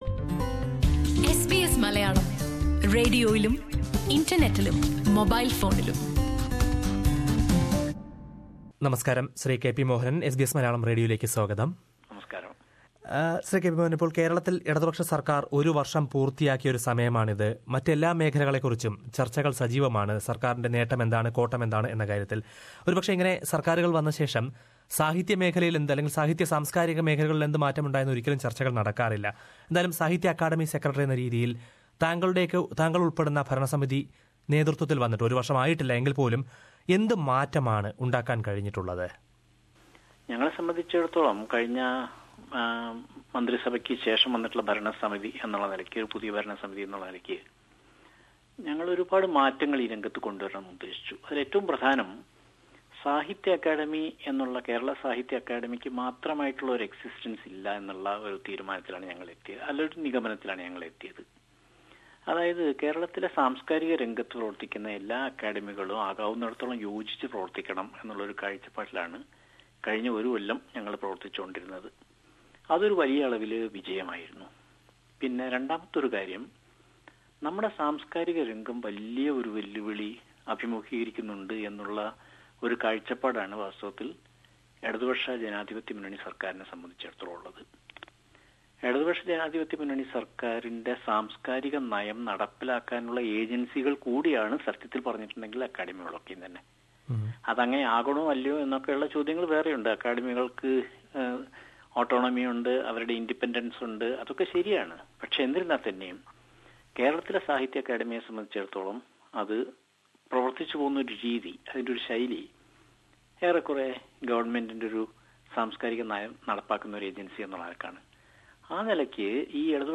ഓസ്‌ട്രേലിയന്‍ സന്ദര്‍ശനത്തിനിടെ എസ് ബി എസ് മലയാളം റേഡിയോയുമായി സംസാരിക്കുകയായിരുന്നു അദ്ദേഹം. പ്രവാസി എഴുത്തുകാര്‍ക്ക് അക്കാദമിയുടെ പ്രത്യേക പുരസ്‌കാരം നല്‍കുന്ന കാര്യം പരിഗണനയിലുണ്ടെന്ന് അദ്ദേഹം അറിയിച്ചു.